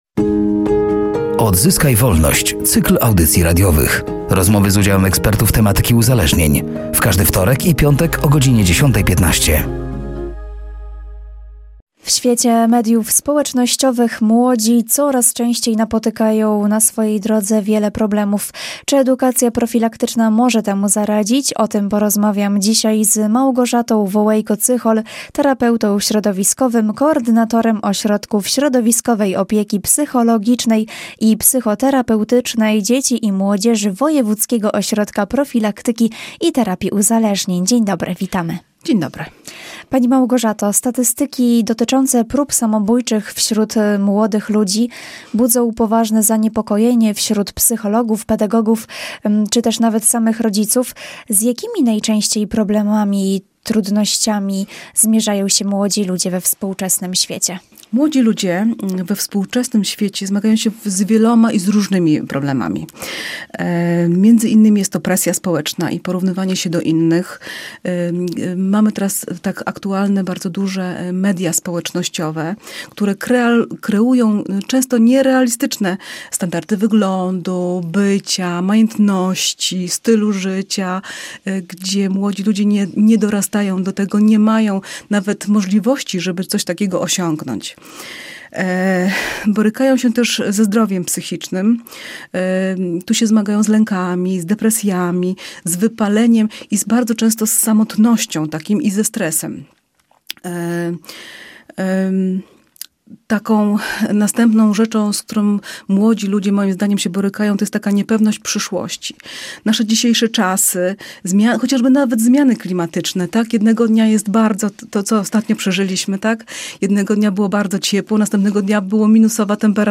„Odzyskaj Wolność”, to cykl audycji radiowych poświęconych profilaktyce uzależnień wśród dzieci i młodzieży.